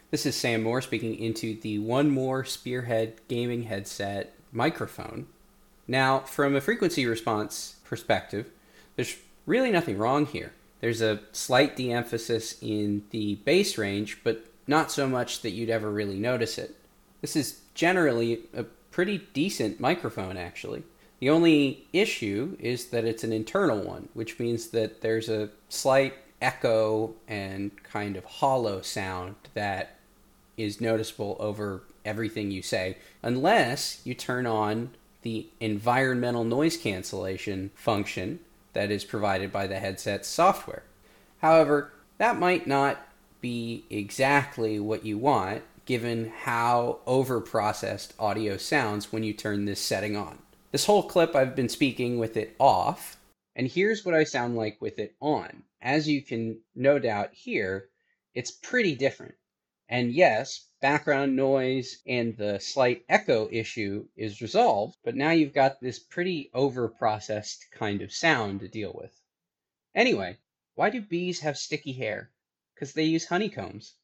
1more-spearhead-mic-sample-redo.mp3